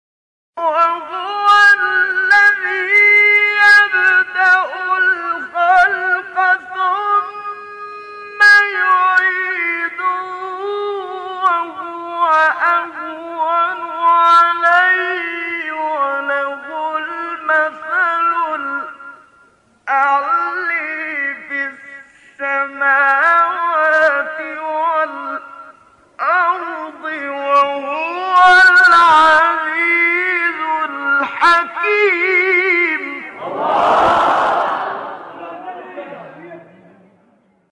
گروه فعالیت‌های قرآنی: مقاطعی صوتی از قاریان برجسته جهان اسلام که در مقام رست اجرا شده‌اند، ارائه می‌شود.
مقام رست